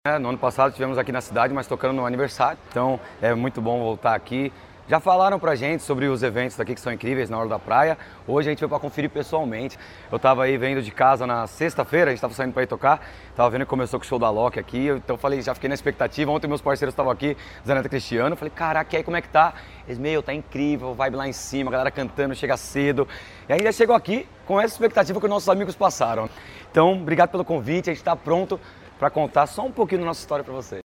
Sonora do vocalista do grupo Jeito Moleque